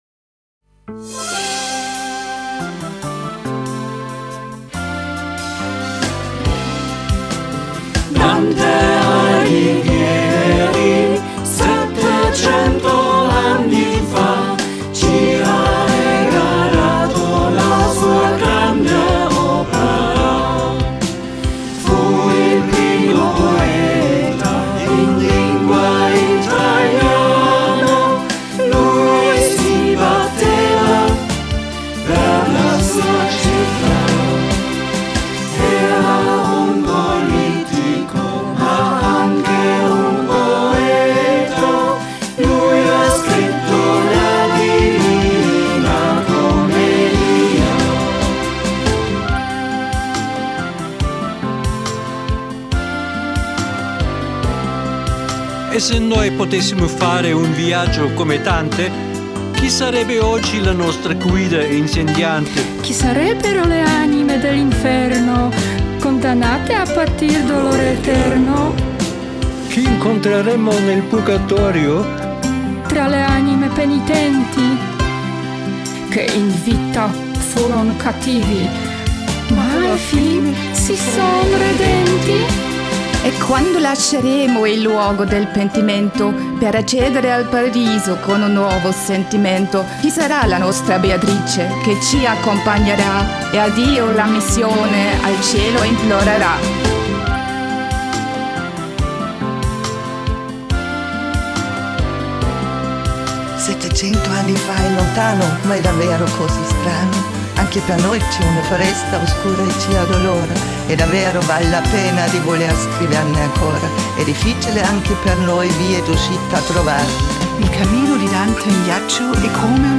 dreistimmiger Refrain
gesprochene Strophen